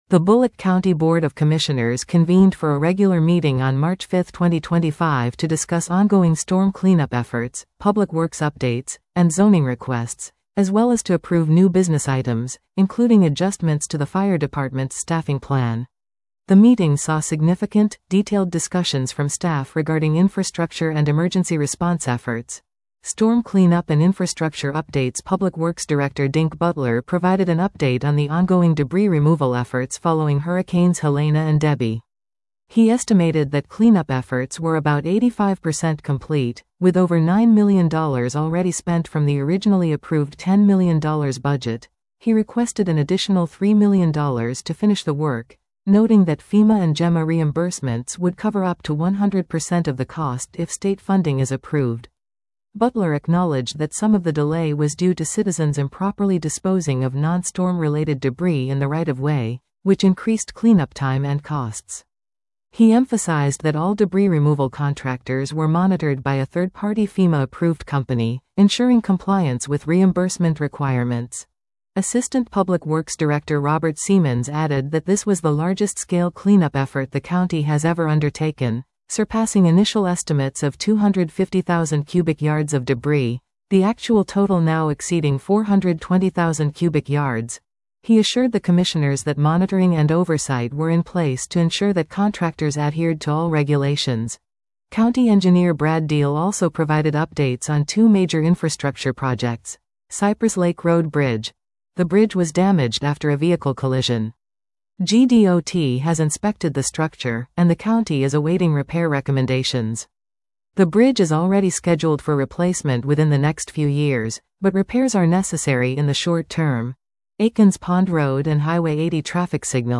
The Bulloch County Board of Commissioners convened for a regular meeting on March 5, 2025 to discuss ongoing storm cleanup efforts, public works updates, and zoning requests, as well as to approve new business items, including adjustments to the fire department’s staffing plan.